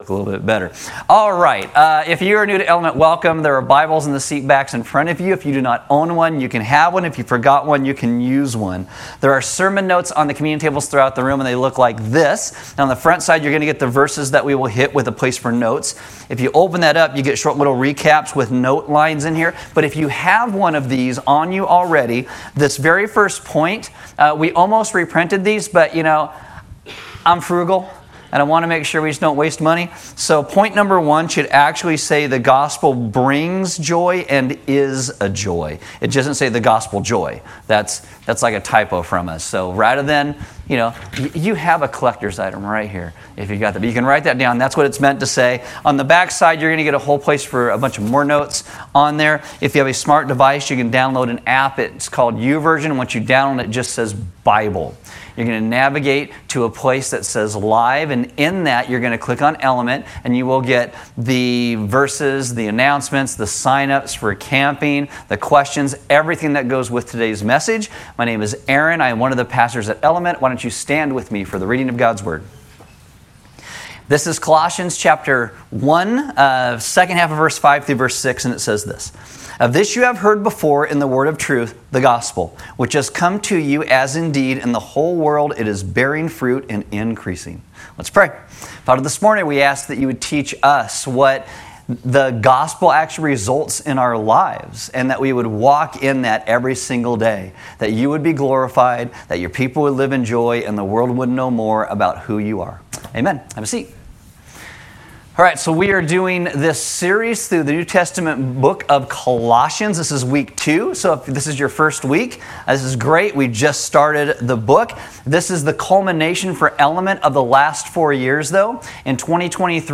A message from the series "Colossians."